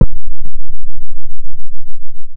Hit.m4a